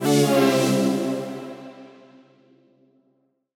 Index of /musicradar/future-rave-samples/Poly Chord Hits/Ramp Down
FR_ZString[dwn]-C.wav